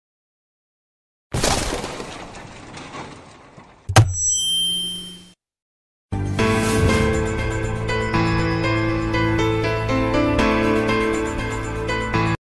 Mic Drop Sound Button: Unblocked Meme Soundboard
Play the iconic Mic Drop sound button for your meme soundboard!